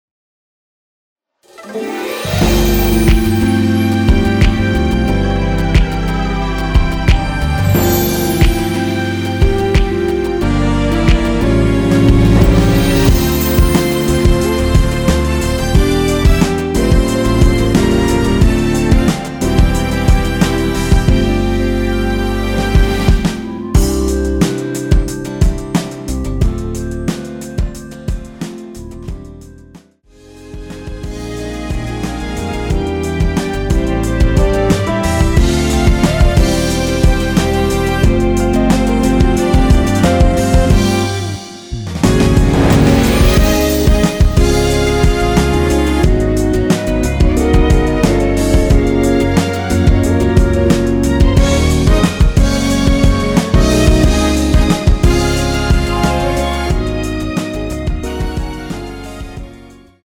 원키에서(-3) 내린 멜로디 포함된 MR입니다.
◈ 곡명 옆 (-1)은 반음 내림, (+1)은 반음 올림 입니다.
멜로디 MR이라고 합니다.
앞부분30초, 뒷부분30초씩 편집해서 올려 드리고 있습니다.
중간에 음이 끈어지고 다시 나오는 이유는